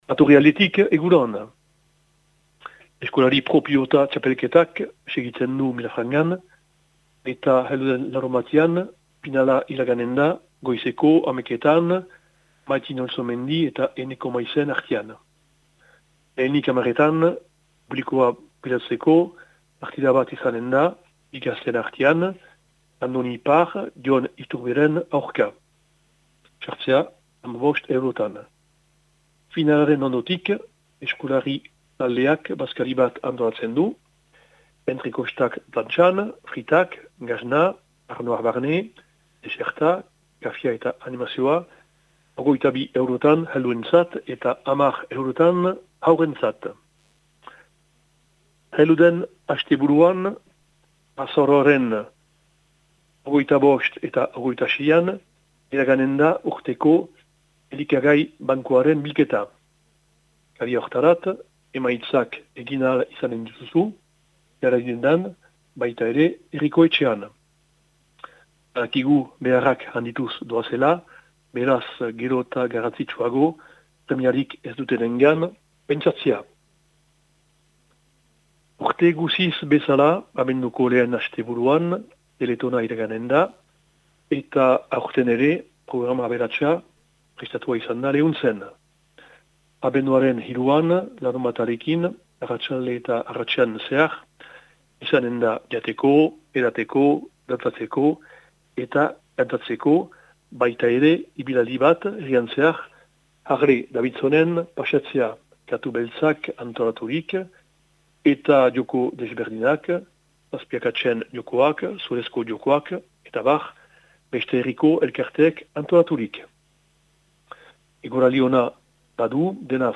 Azaroaren 24ko Lehuntzeko berriak